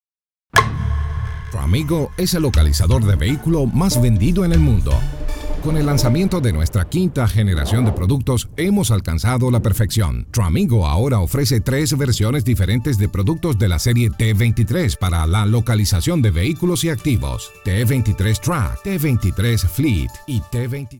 Optimus Sound | Amaze people with the sound of your stories.-Español - Venezuela - Hombre
Voice Talent ESVEM001